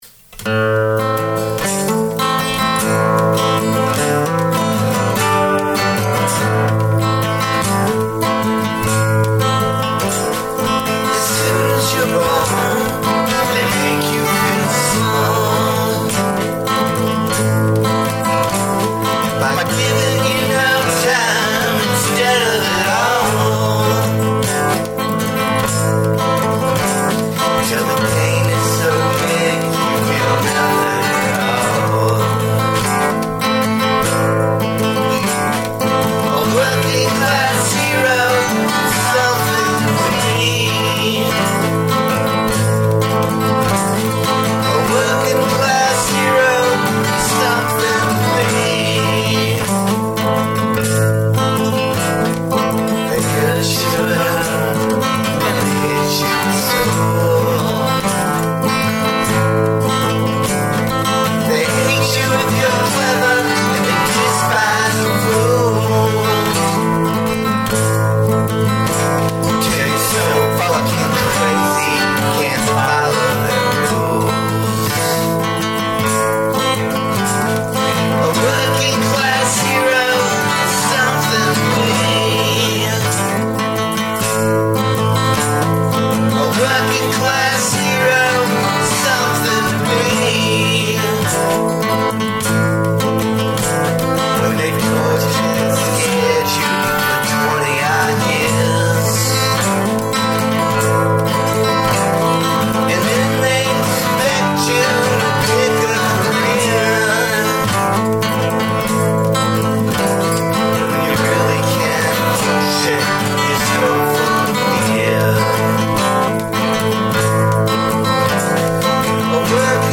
Adding an octave guitar part, and a bit of percussion.